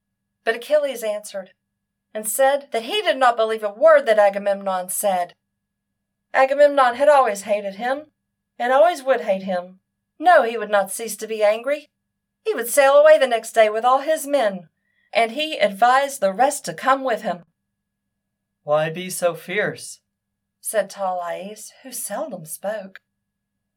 I have a high female voice.
Here is a sample of a scooter increasing in volume and
A sample of my voice loud, soft, high, low.
Too much noise reduction. The voice quality would be fine but for the talking into a milk jug or wine glass honky sound.
There’s also a litle slap or echo in there.